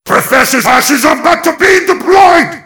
mvm_bomb_alerts04.mp3